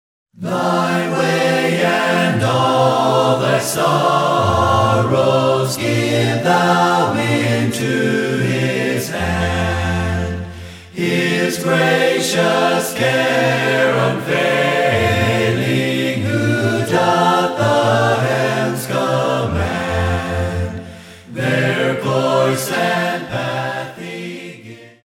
singing 16 hymns a cappella.